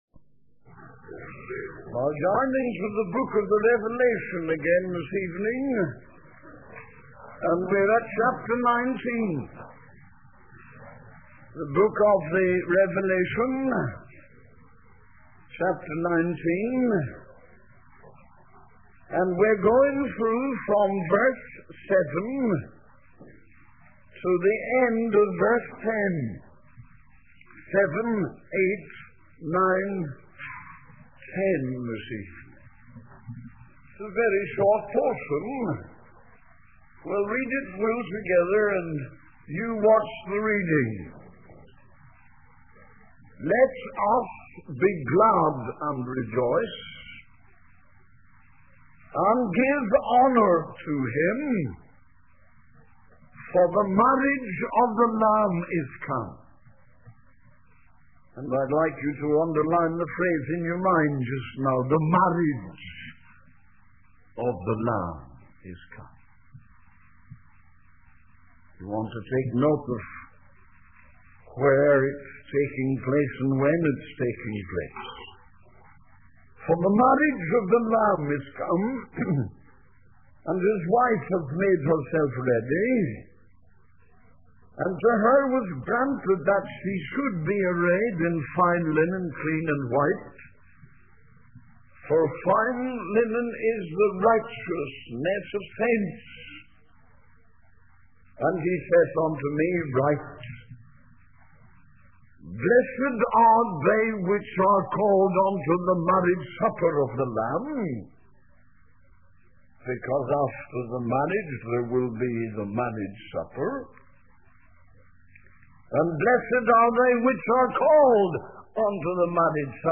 In this sermon, the preacher discusses the concept of the gospel supper and the invitation to come and partake in it. He emphasizes that the gospel offers forgiveness, redemption, justification, substitution, and reconciliation. The sermon then transitions to a discussion of the book of Revelation, highlighting the different scenes that take place on earth and in heaven.